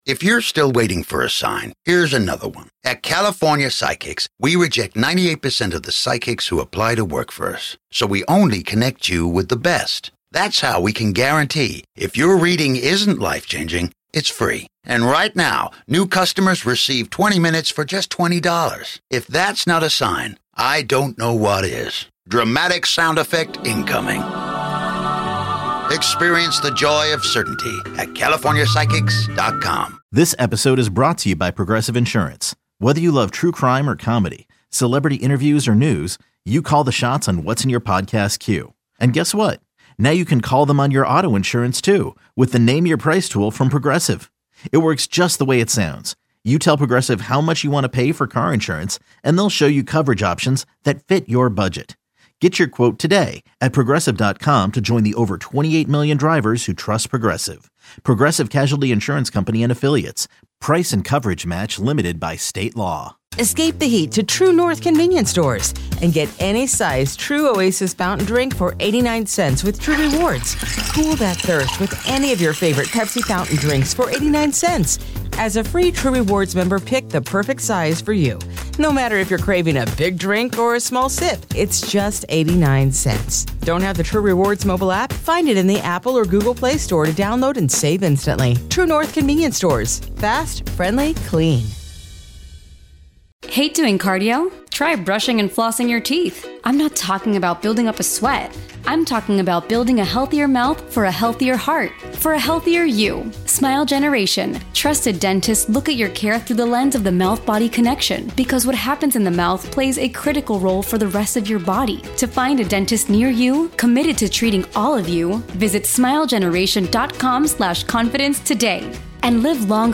Rob Riggle In Studio.